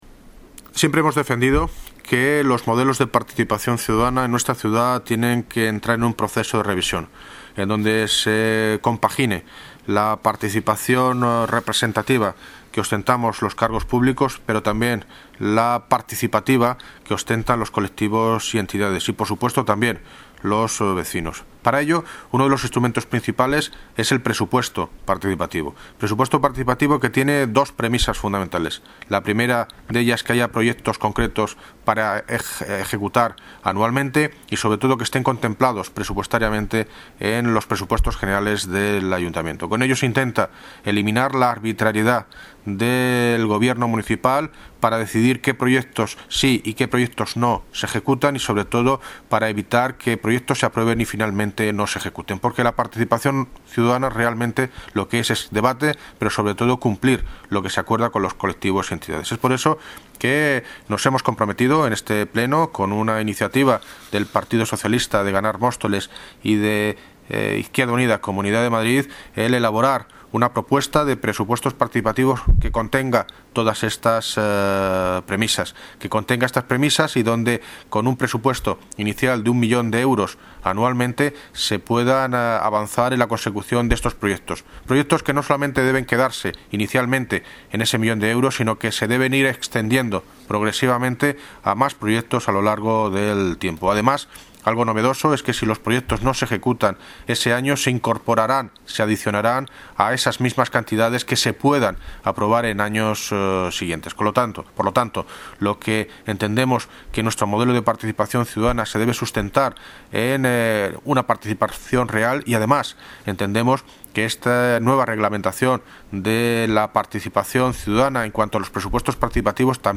Audio - David Lucas (Alcalde de Móstoles) Sobre Presupuestos Participativos
Audio - David Lucas (Alcalde de Móstoles) Sobre Presupuestos Participativos.mp3